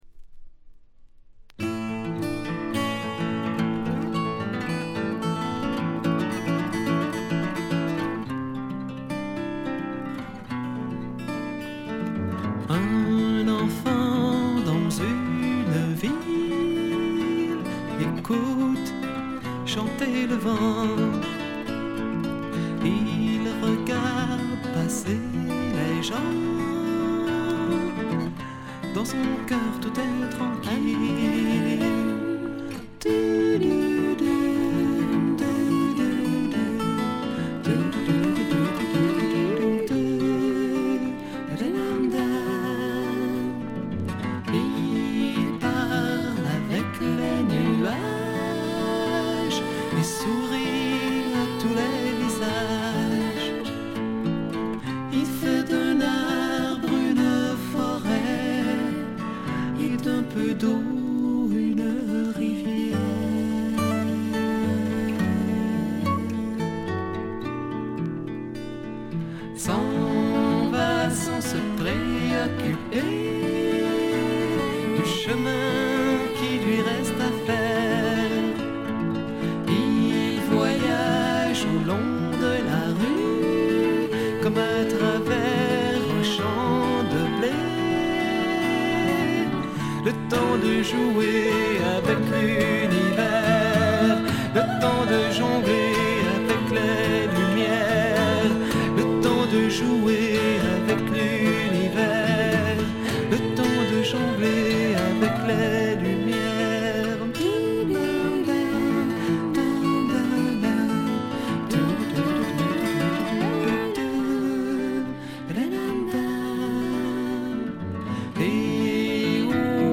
A2序盤で散発的なプツ音が2回。
カナダ・ケベックを代表する兄妹フレンチ・ヒッピー・フォーク・デュオによる名盤です。
本作は特に幻想的な表現に磨きがかかっており、浮遊感漂う夢見心地な感覚は絶品ですね。
試聴曲は現品からの取り込み音源です。
Guitar, Vocals